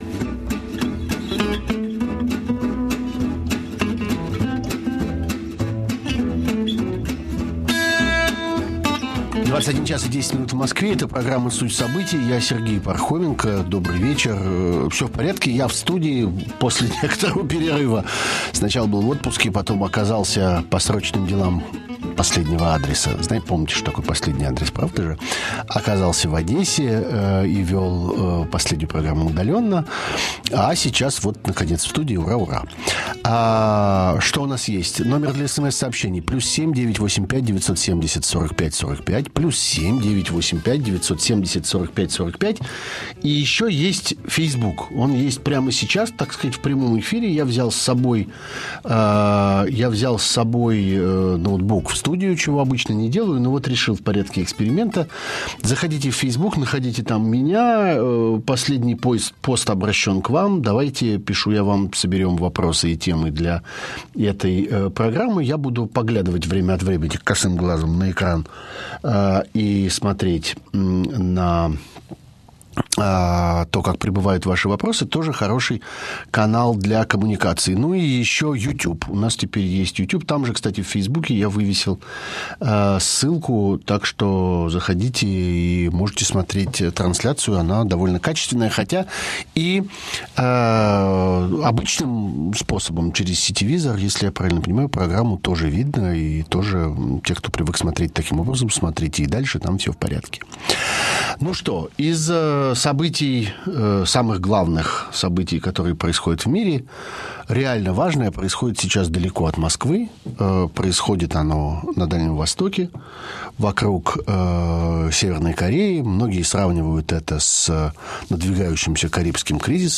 Всё в порядке, я в студии после некоторого перерыва.